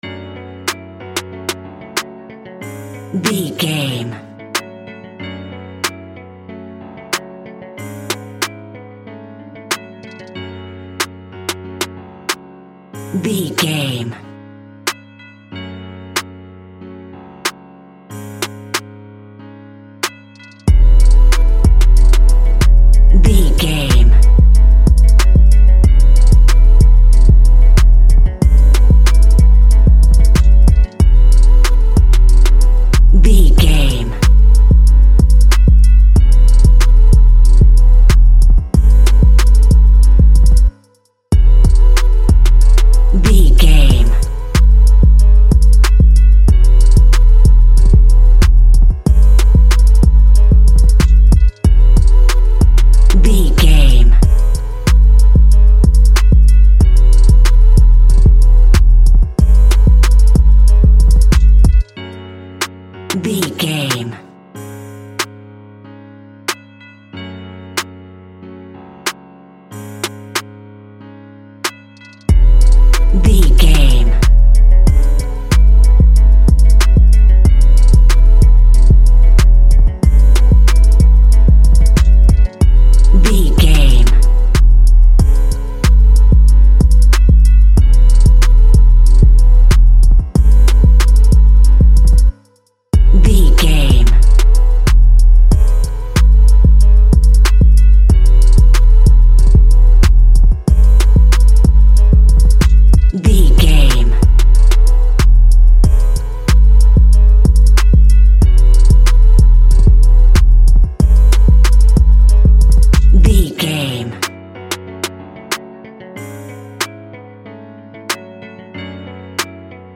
Category: Music